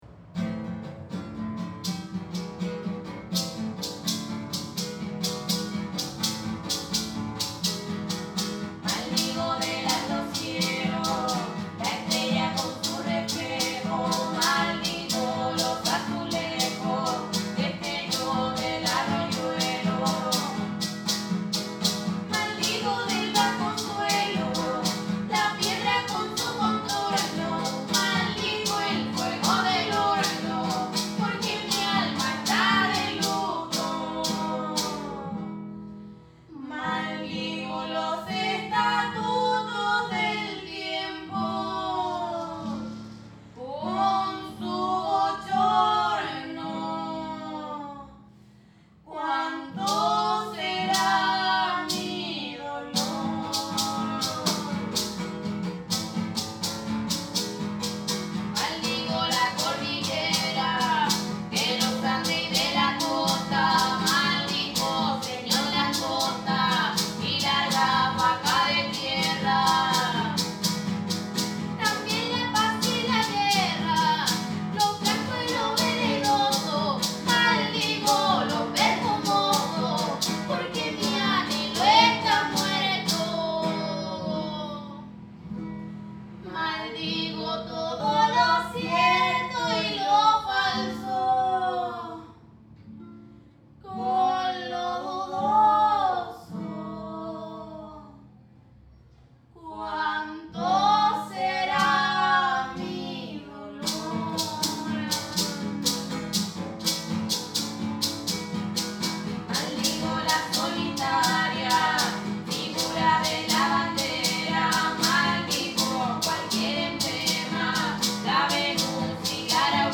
Two girls were singing in harmony, and I did not had the time to ask her where they were coming from.